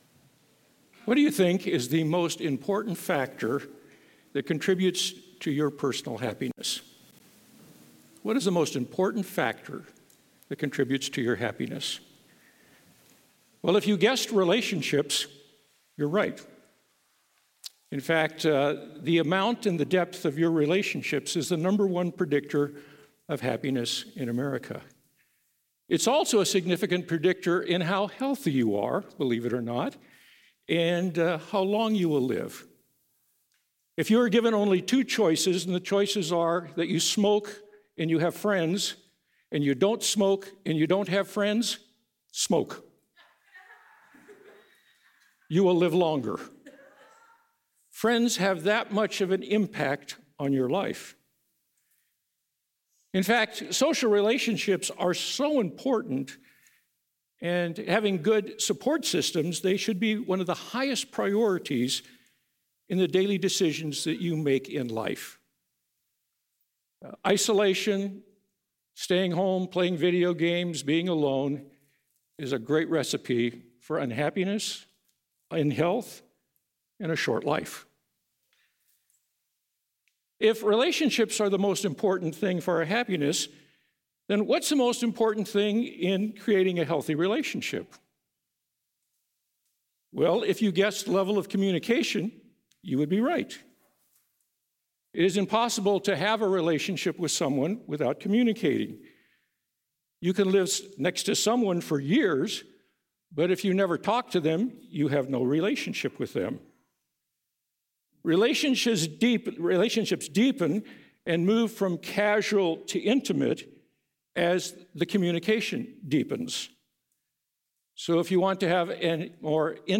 Trinity Church Portland